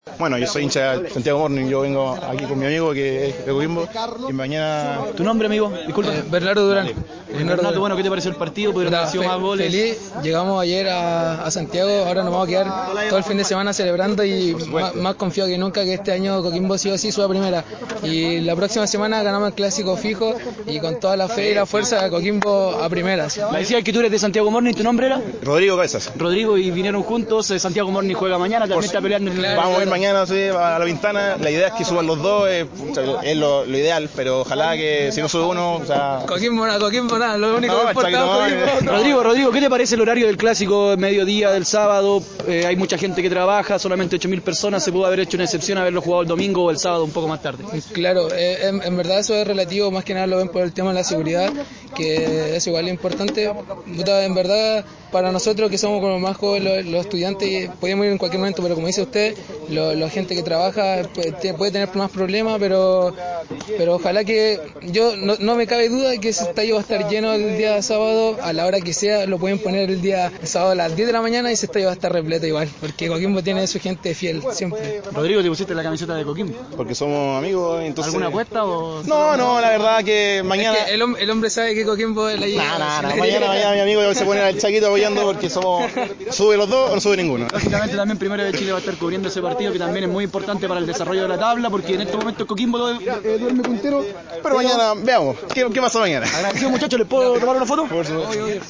Escuche el testimonio de ambos.